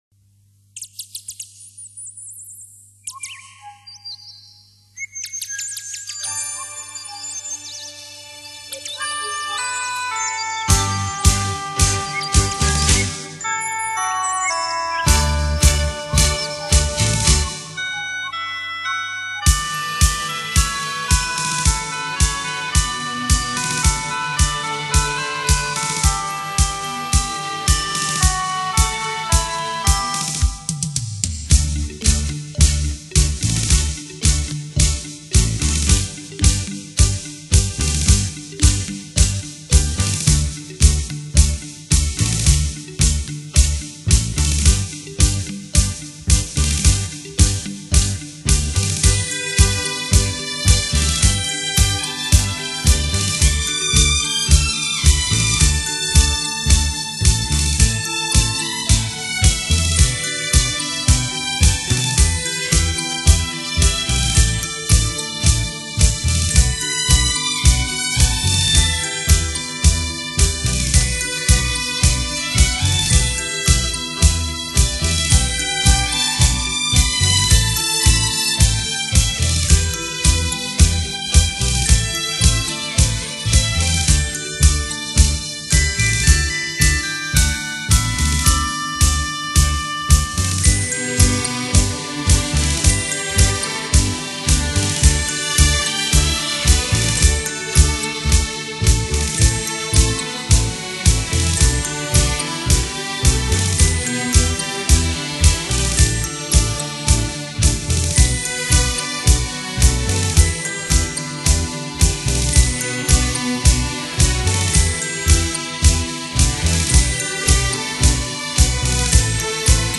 怀旧舞曲精选
探戈